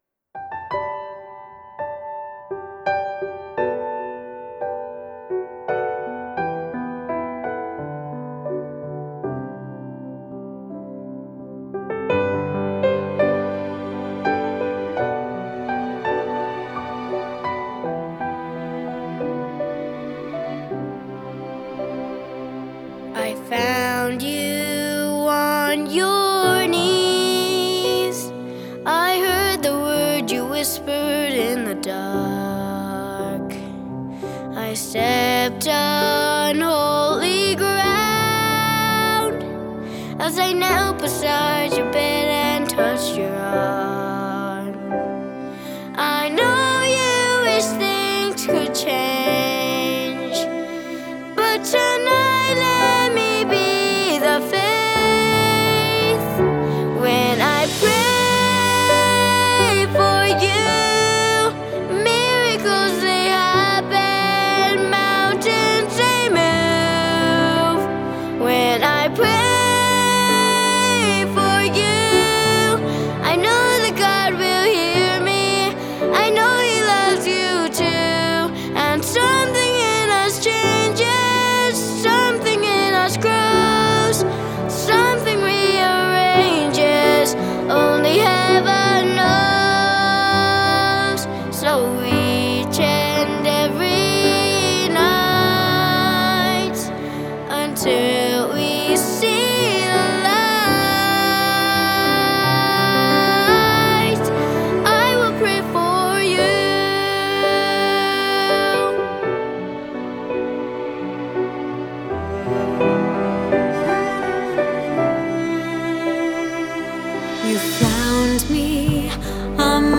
It is a duet with my youngest son